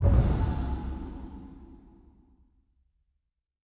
Special Click 01.wav